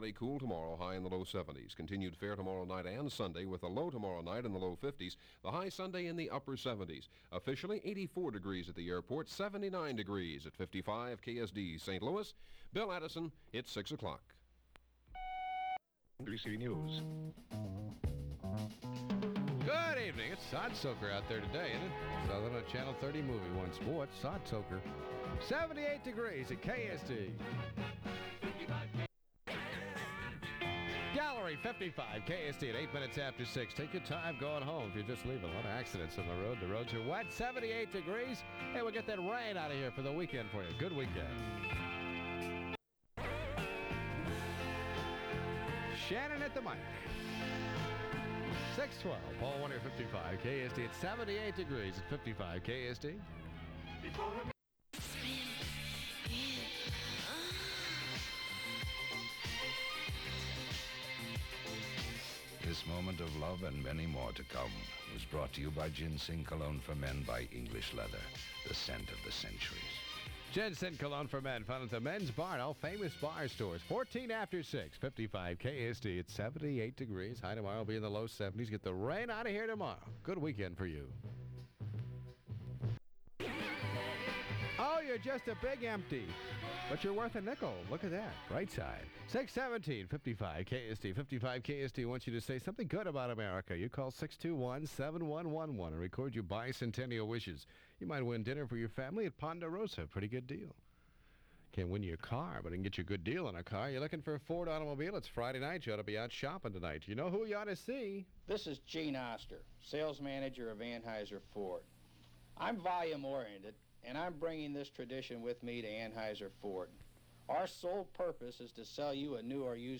Original Format aircheck